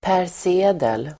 Ladda ner uttalet
persedel substantiv, article of clothing , item of equipment Uttal: [pär_s'e:del] Böjningar: persedeln, persedlar Definition: utrustningsföremål; klädesplagg article substantiv, sak , artikel [handel], vara , persedel